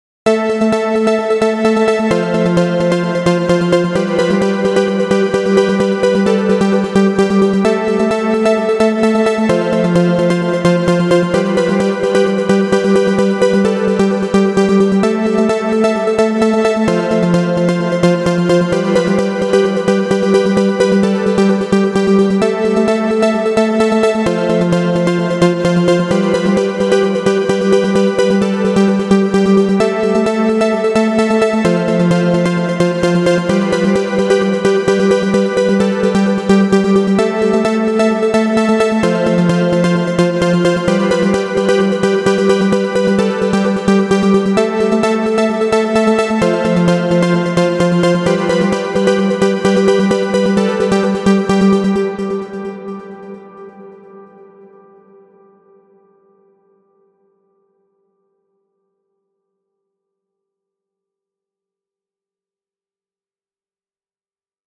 громкие
веселые
без слов
Electronica
progressive house
Космическая музыка